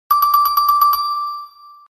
Вы можете слушать онлайн и скачивать различные вопросительные интонации, загадочные мелодии и звуковые эффекты, создающие атмосферу тайны.
Вопросительные знаки выскакивают